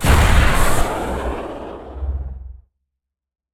ships / combat / weapons